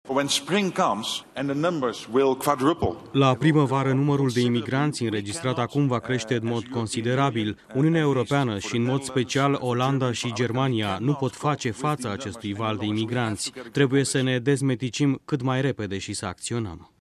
Tot la Davos, premierul olandez Mark Rutte a declarat cã Uniunea Europeanã are la dispoziþie între 6 și 8 sãptãmâni pentru a pune sub control afluxul de refugiați proveniți din zone de conflict în special din Orientul Mijlociu: